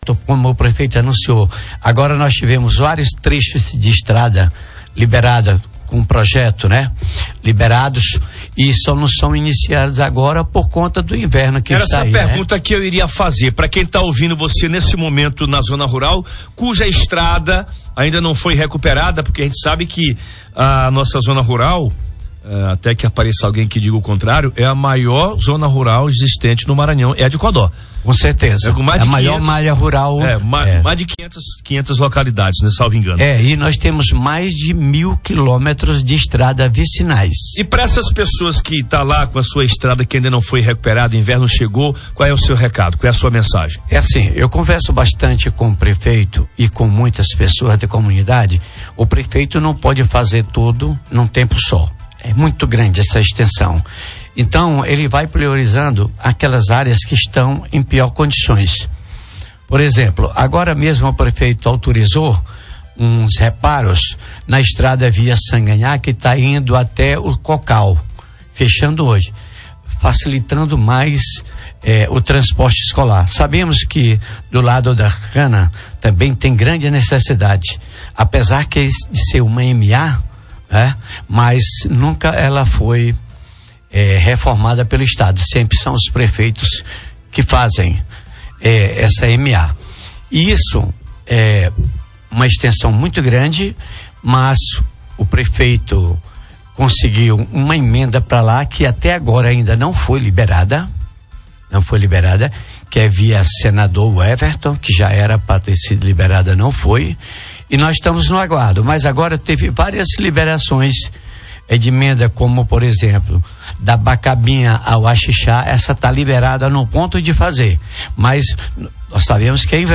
Em entrevista, Presidente da Câmara destaca obras liberadas, ações na zona rural e programação de Carnaval na zona rural de Codó